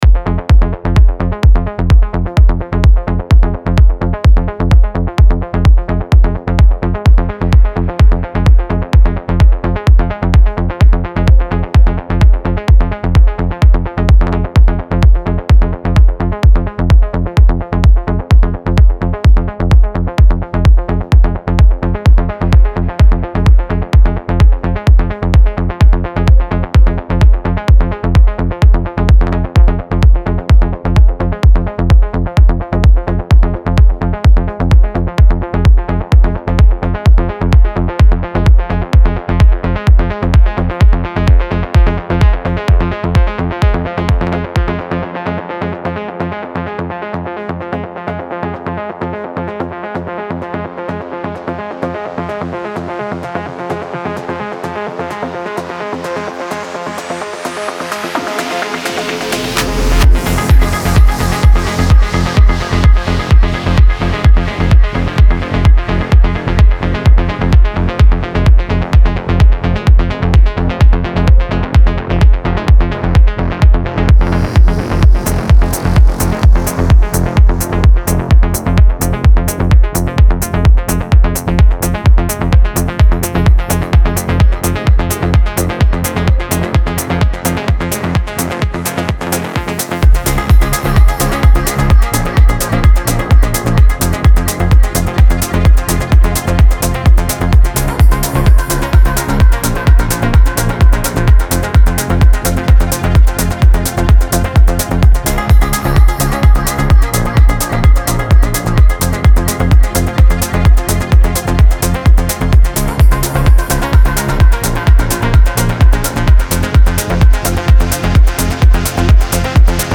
Жанр: Electro